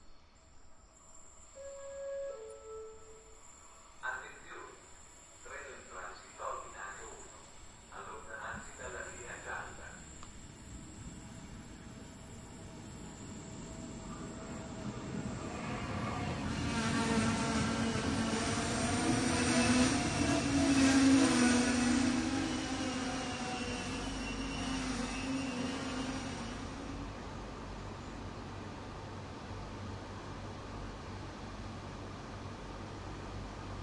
描述：录音：在火车站，火车停了，比走铁路的气氛还要好
Tag: 铁路 车站 列车